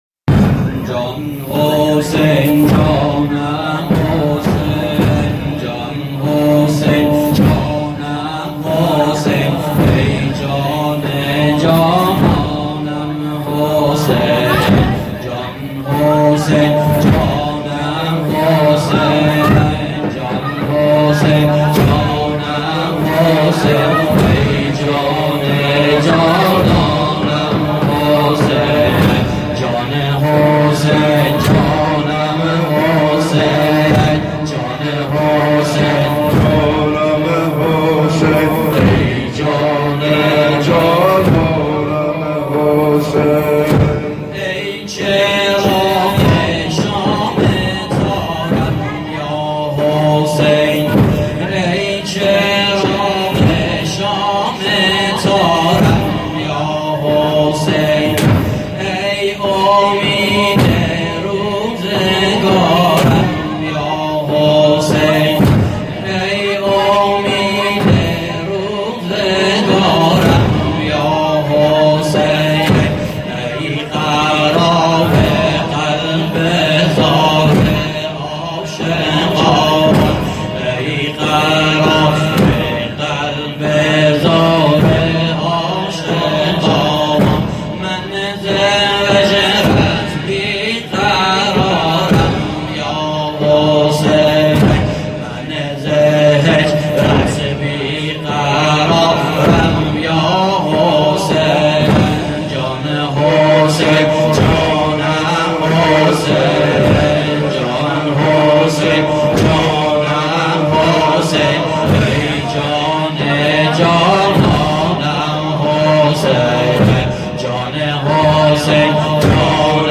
محرم ۹۶(زنجیر زنی)